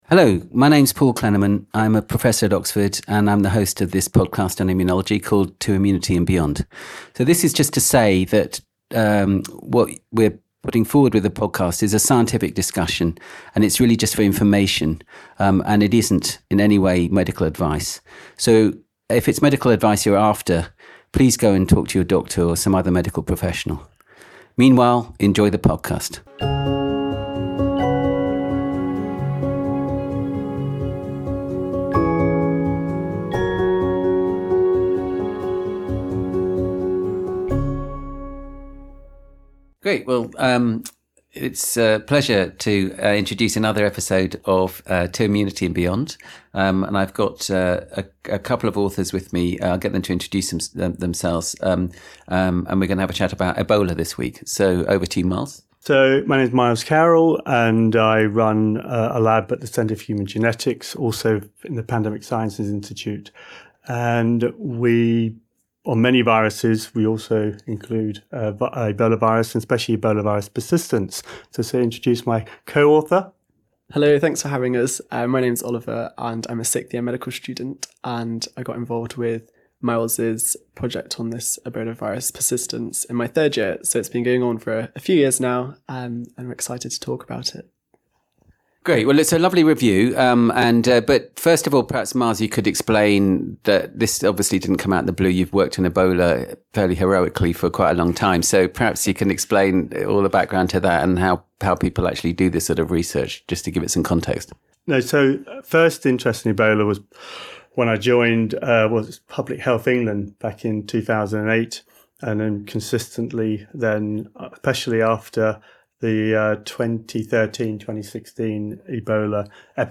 They share insights into how viral reservoirs form in immune-privileged sites and the immunological signatures linked to resistance. The conversation explores the implications of these findings on sexual transmission of EBOV, therapeutic development and strategies to prevent viral persistence and recrudescence. They also consider how these mechanisms may inform approaches to other emerging infectious diseases.